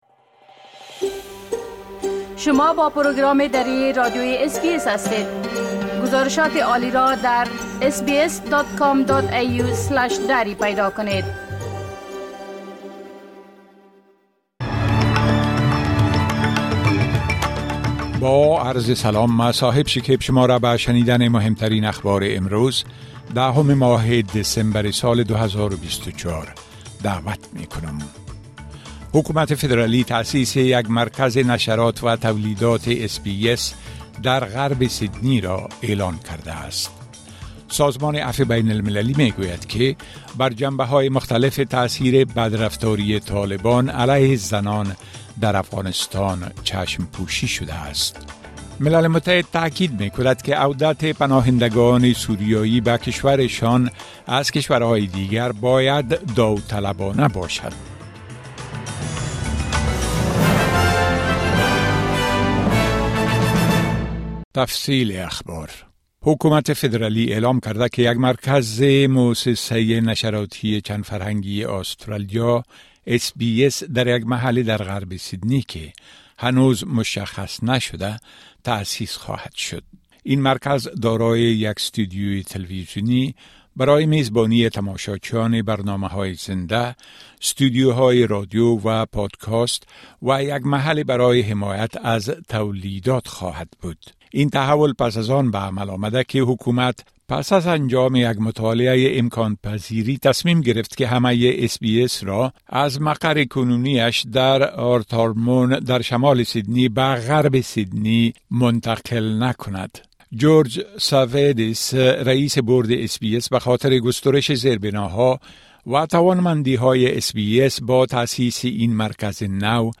مهمترين اخبار روز از بخش درى راديوى اس بى اس